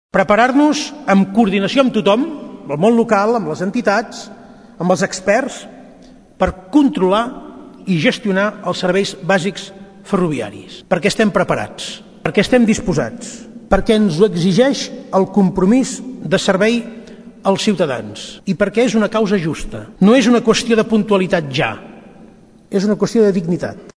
L’acte ha aplegat més de 300 representants del món institucional, empresarial i sindical al Palau de la Generalitat, entre els assistents hi havia l’alcalde de Tordera, Joan Carles Garcia. El president del Govern, Carles Puigdemont, ha lamentat que el servei de Rodalies i Regionals afecta negativament la competitivitat de l’economia catalana, la qualitat de vida dels ciutadans i el medi ambient del país.